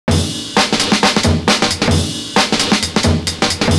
KRISHNA BEAT.wav